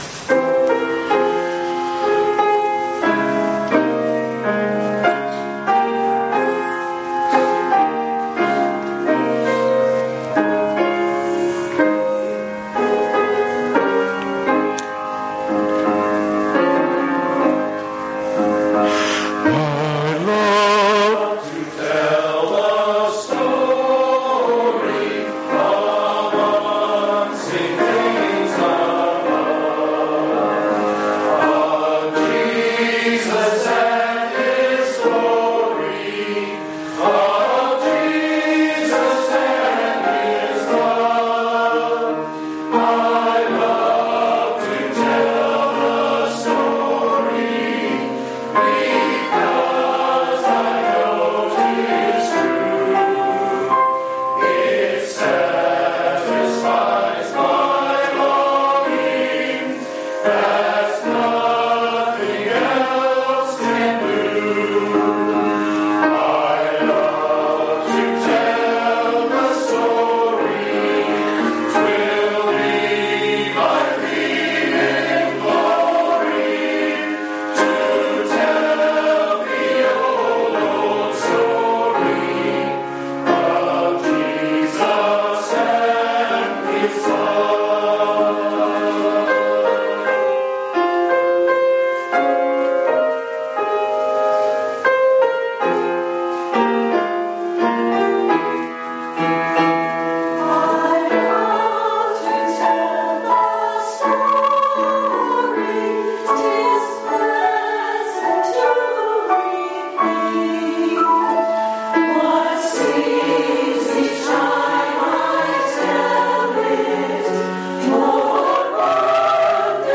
LISTEN • 27 Oct 2019 • I Love to Tell the Story • Chancel Choir • 3:51